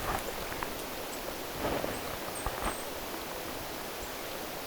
mikä tiaislaji?